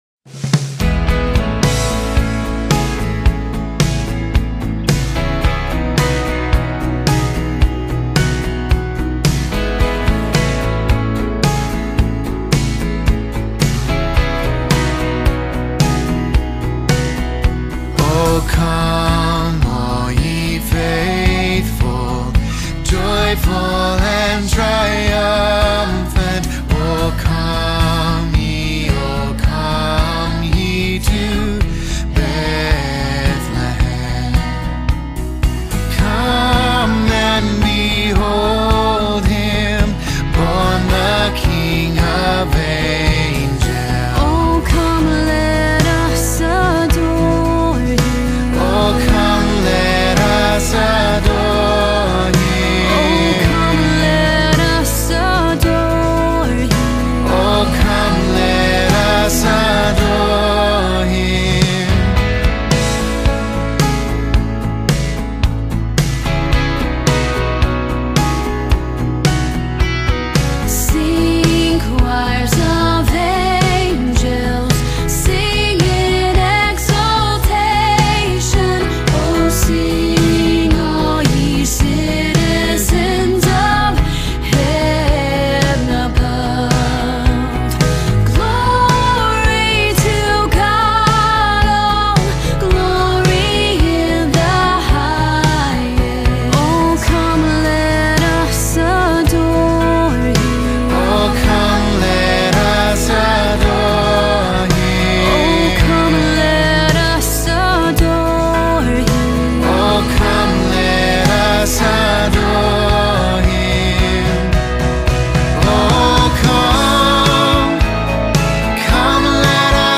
River Kids Choir 2025 | The River Church